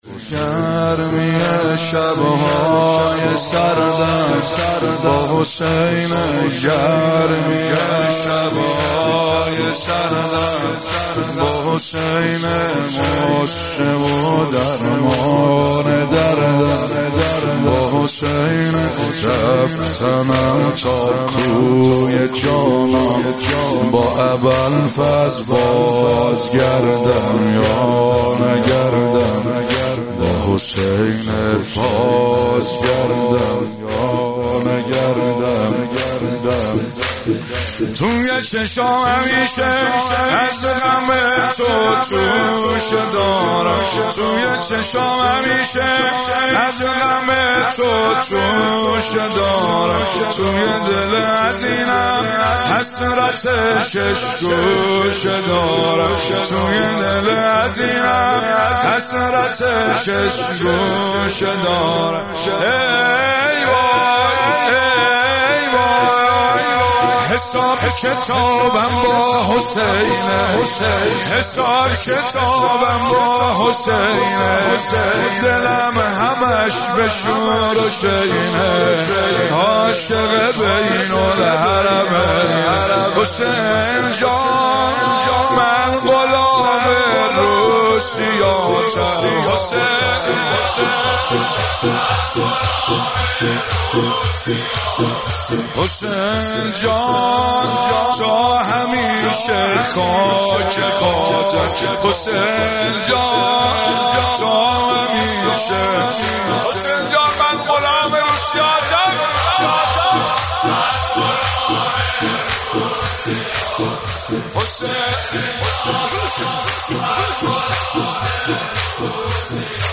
زمینه (جلسه هفتگی، ۵دی)
زمینه جلسه ع